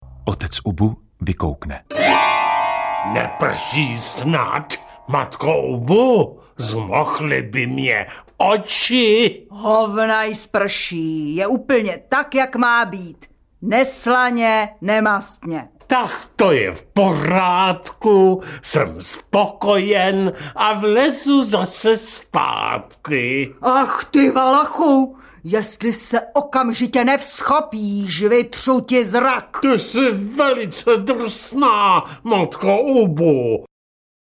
Dramatická variace na téma Alfreda Jarryho. Rozhlasová nahrávka z roku 1999.